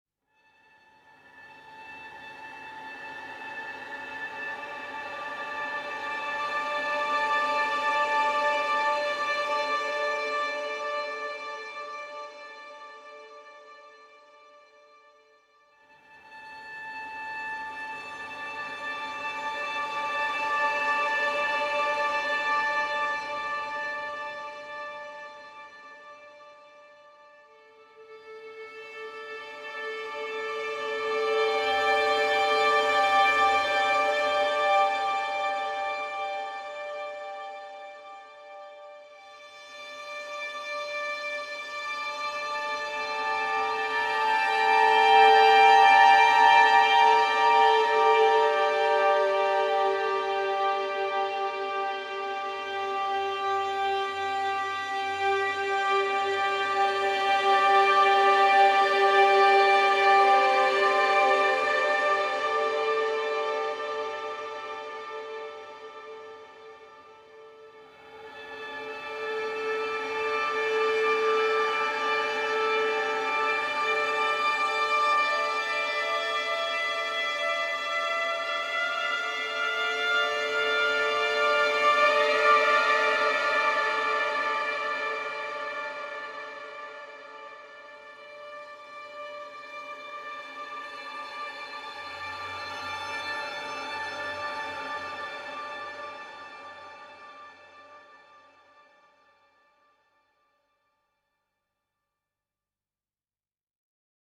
String Drone Version